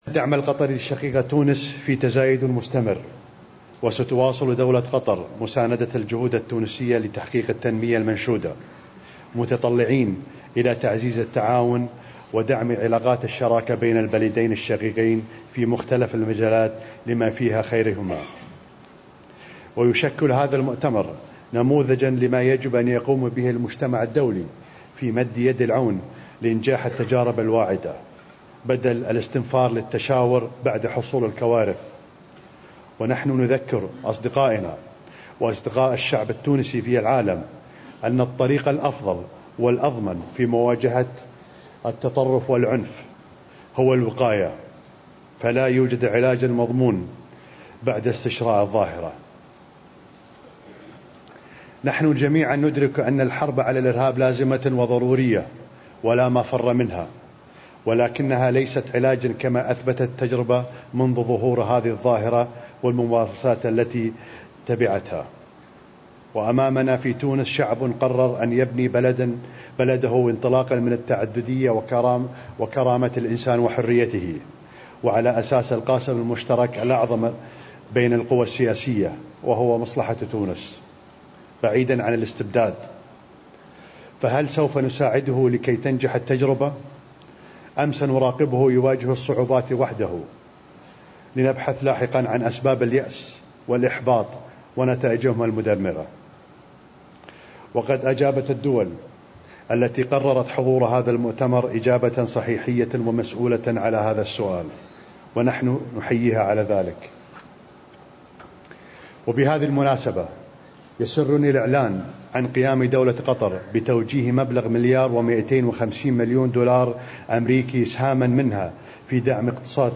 L'Emir du Qatar a annoncé, mardi lors de la cérémonie d'ouverture de la conférence internationale sur l'investissement, la mise à disposition d'un fonds qatari de 1250 millions de dollars américains dédié au soutien du développement économique tunisien.